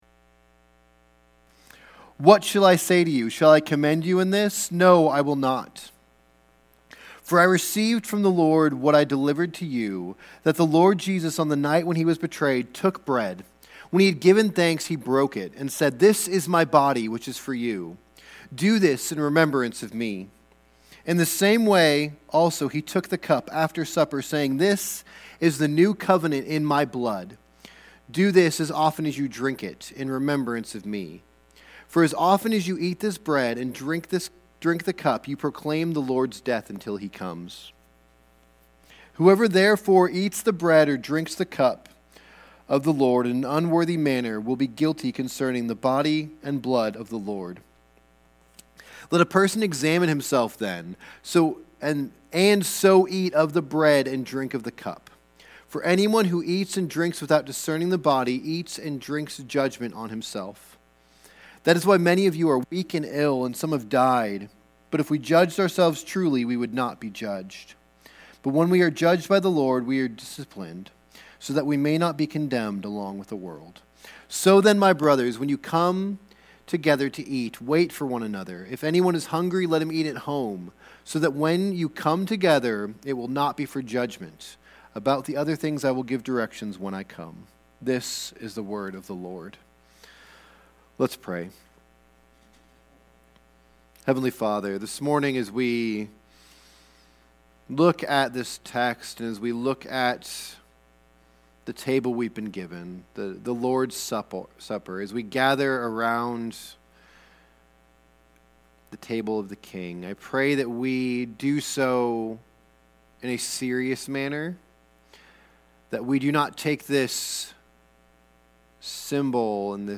Sermons | Maranatha Baptist Church